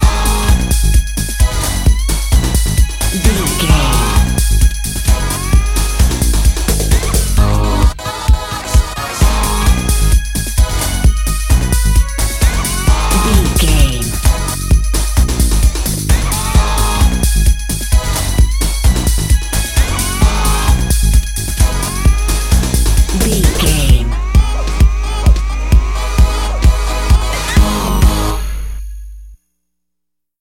Aeolian/Minor
A♭
Fast
drum machine
synthesiser
Eurodance